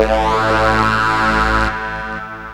tekTTE63036acid-A.wav